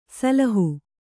♪ salahu